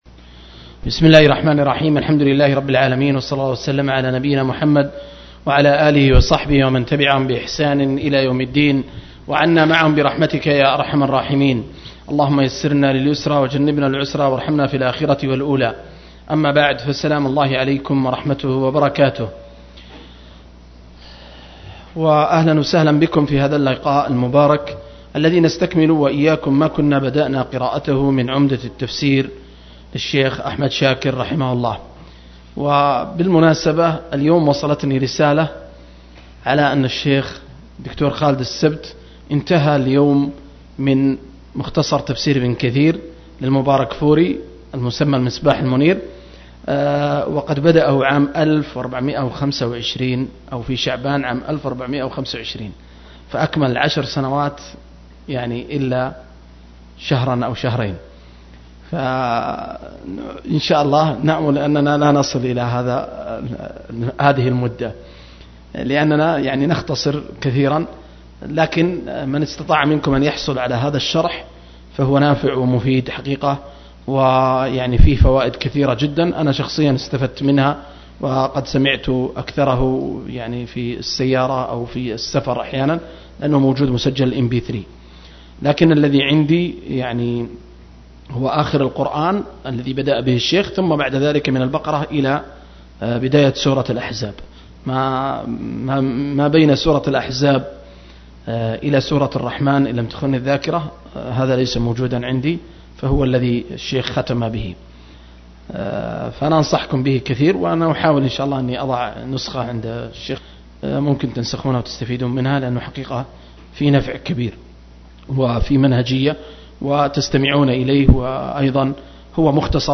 022- عمدة التفسير عن الحافظ ابن كثير – قراءة وتعليق – تفسير سورة البقرة (الآيات 97-102)